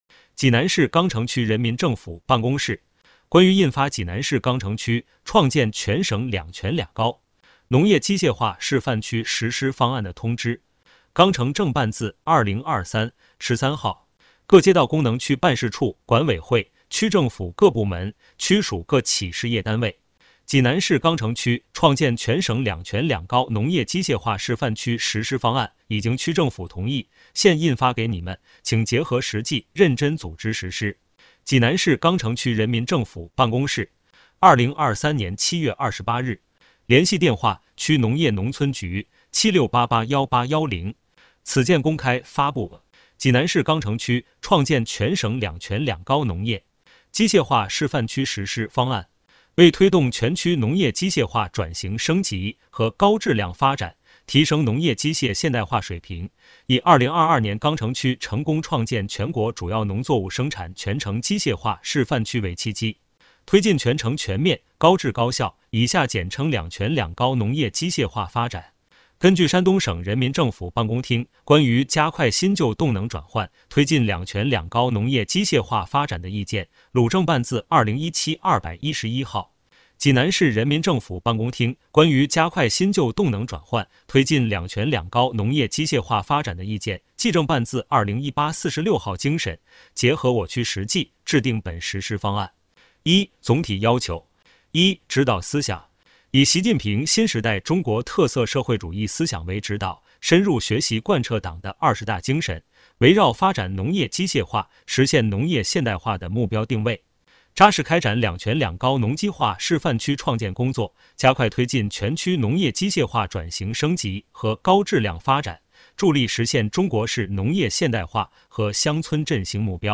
有声朗读：济南市钢城区创建全省“两全两高”农业机械化示范区（县）实施方案